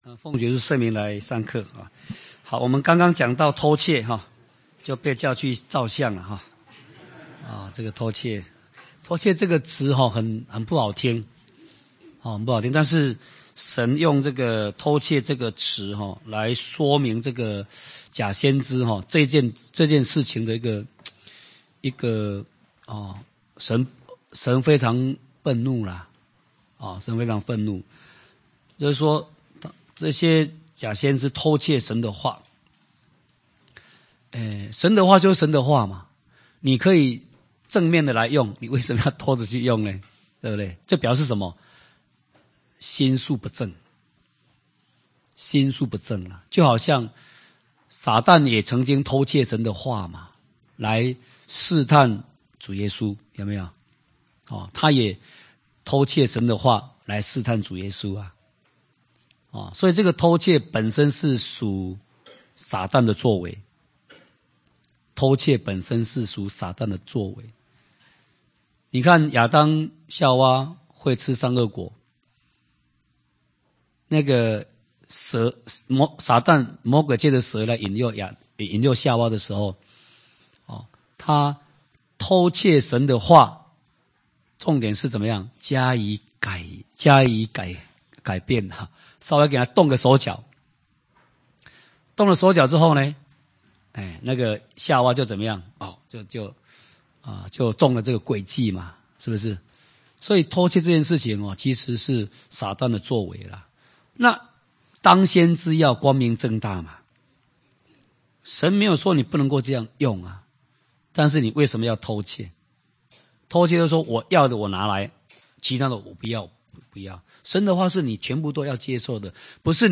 類別 講習會
地點 台灣總會 日期 02/17/2011 檔案下載 列印本頁 分享好友 意見反應 Series more » • 耶利米書44-01：緒論(1